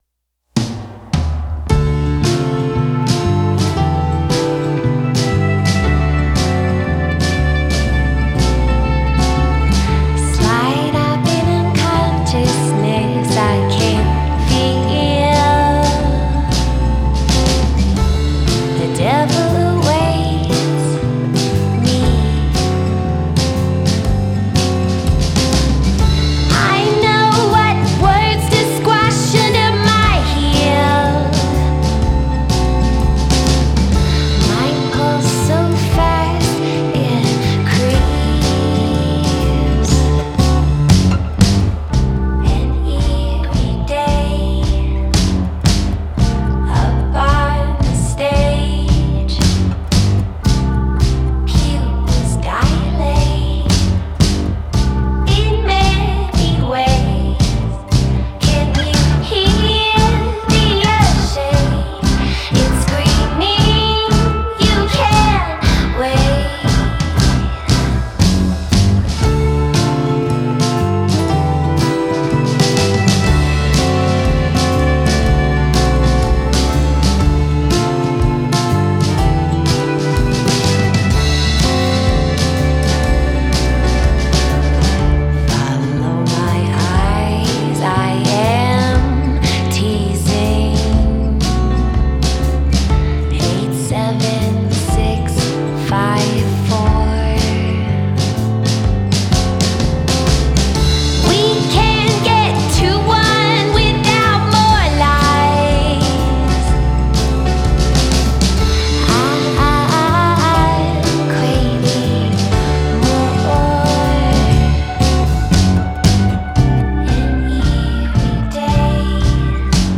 Genre: Indie Rock / Folk